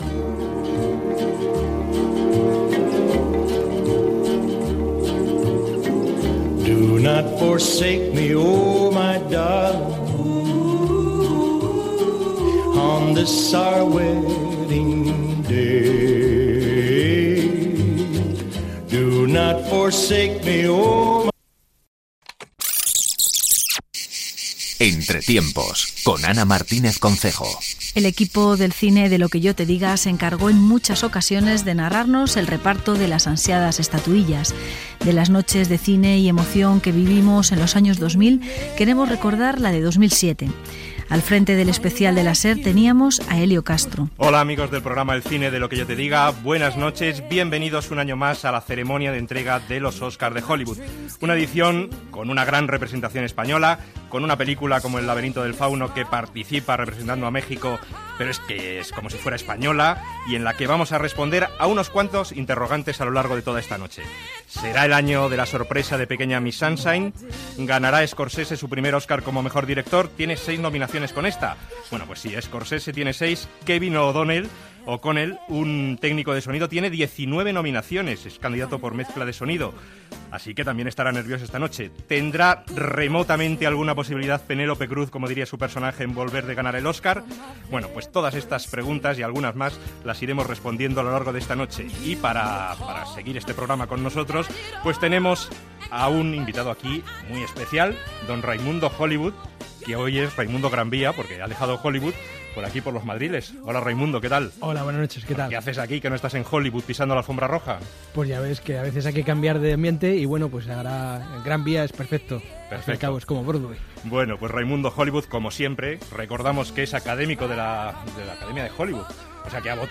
Presentació de l'especial per informar de la cerimònia de lliurament dels premis Oscar, connexió amb el Kodak Theatre de la ciutat de Los Angeles per informar de l'arribada dels invitats que passen per la catifa vermella